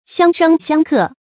相生相克 xiāng shēng xiāng kè
相生相克发音